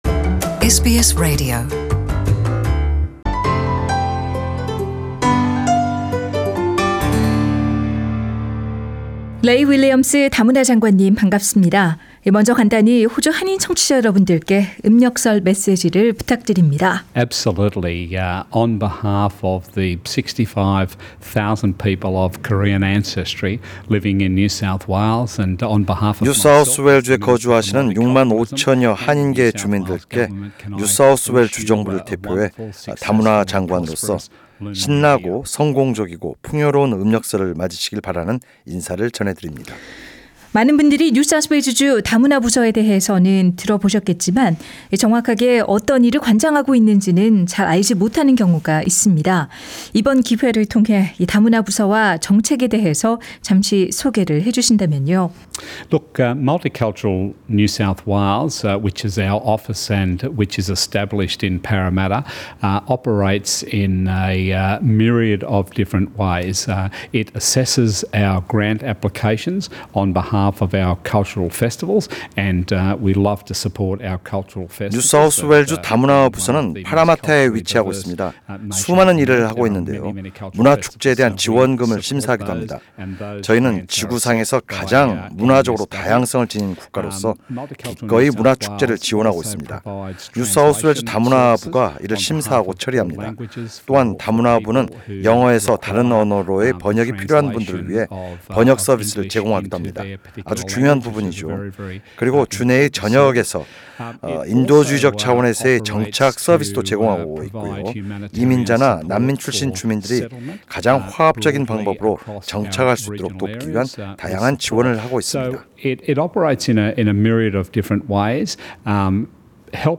[설 특집 대담] 레이 윌리엄스 NSW 주 다문화 장관